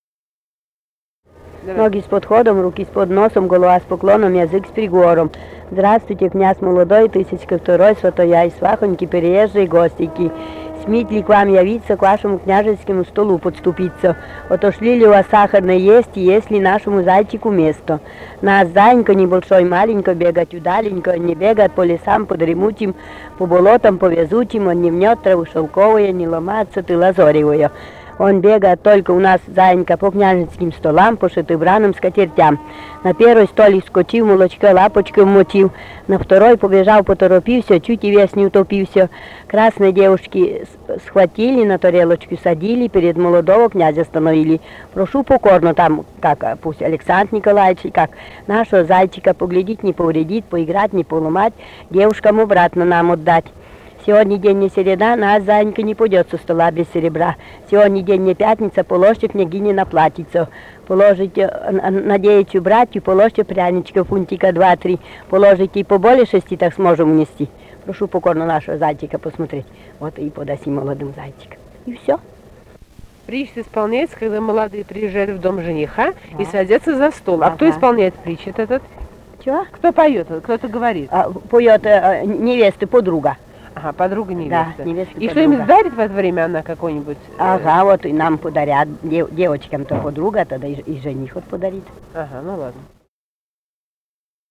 Вологодская область, д. Осподаревская Тигинского с/с Вожегодского района, 1969 г. И1130-11